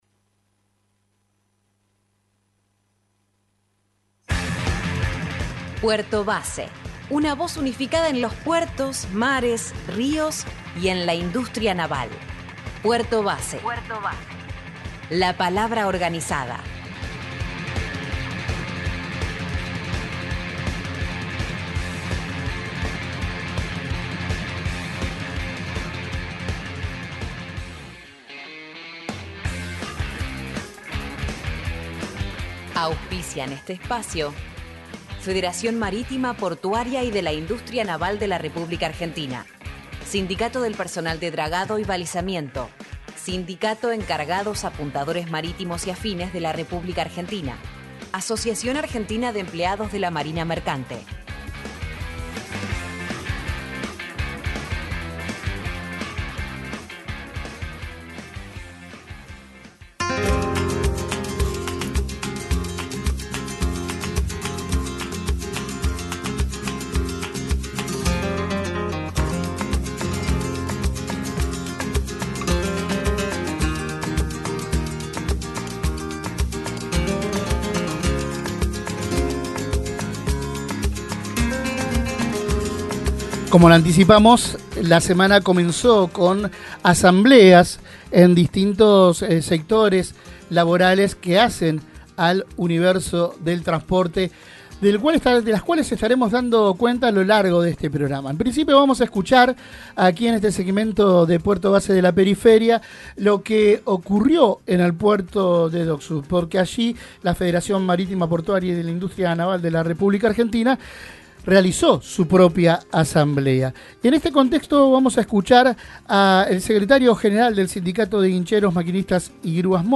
En Puerto Base de la Periferia, recogimos el testimonio